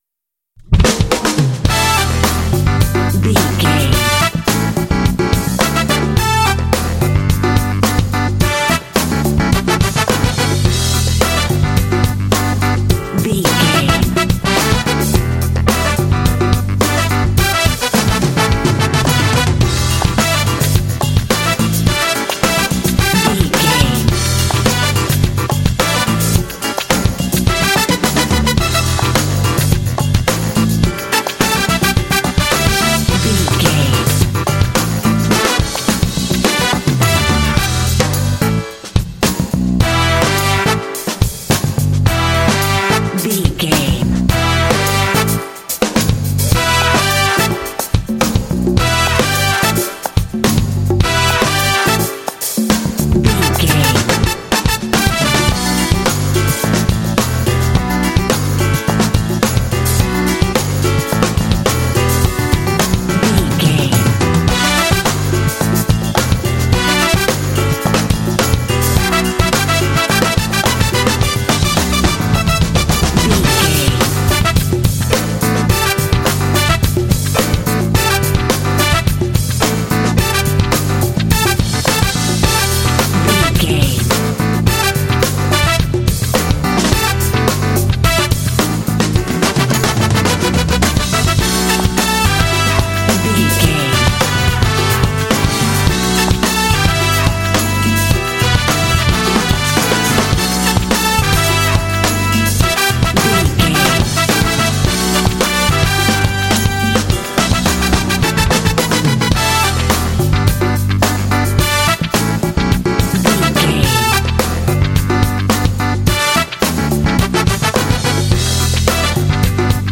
Uplifting
Dorian
groovy
driving
energetic
brass
drums
bass guitar
electric guitar
saxophone
piano